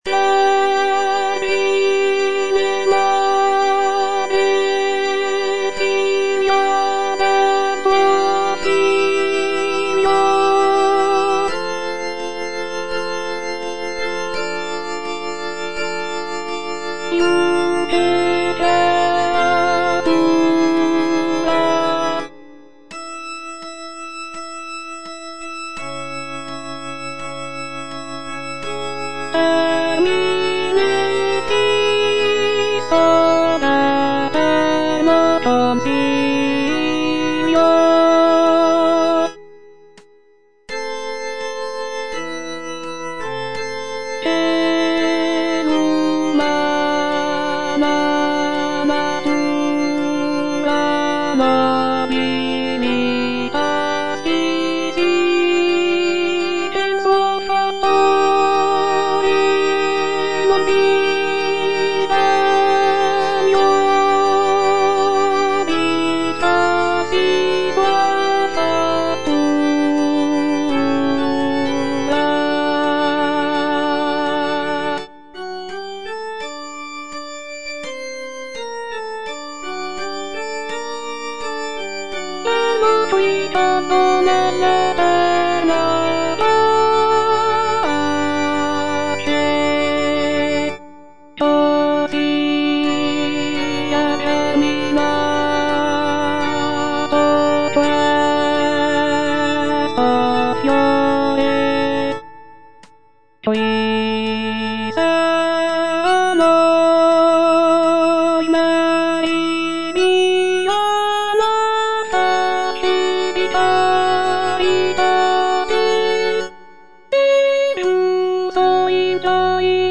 G. VERDI - LAUDI ALLA VERGINE MARIA Alto I (Voice with metronome) Ads stop:
"Laudi alla Vergine Maria" is a sacred choral work composed by Giuseppe Verdi as part of his "Quattro pezzi sacri" (Four Sacred Pieces). It is a hymn of praise to the Virgin Mary, with text inspired by Dante's Divine Comedy. The piece features lush harmonies and intricate counterpoint, showcasing Verdi's mastery of choral writing.